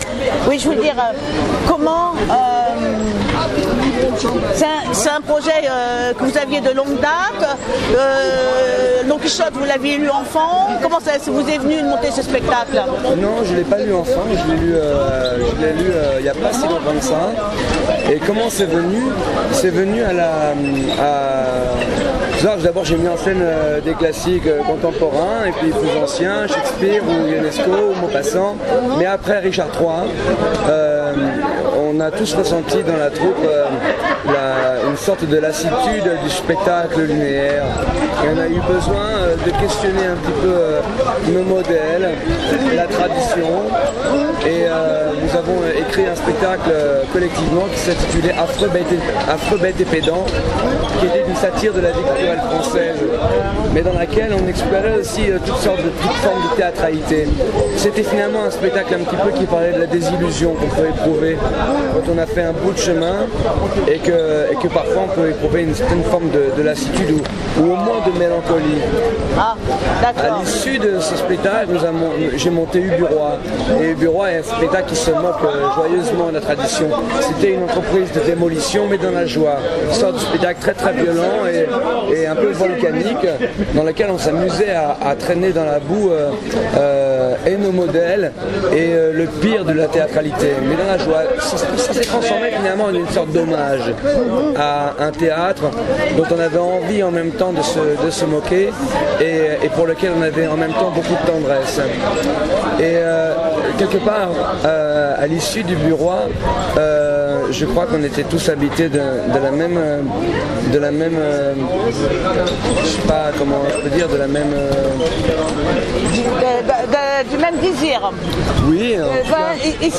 INTERWIEW
à l’issue de la réprésentation du 6 Août 2016